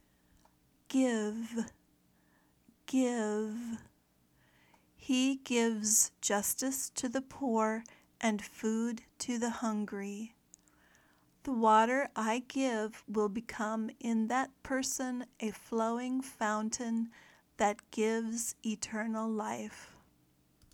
/ɡɪv/ (verb)